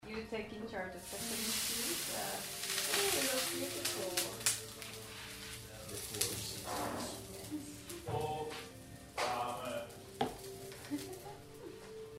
part2-8_you take in charge blabla, and then beautiful and powerful tsss sound.mp3